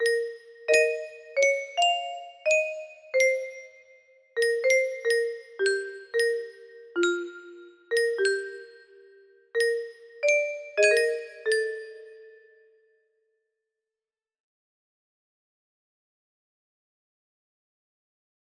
Unknown Artist - Untitled wip music box melody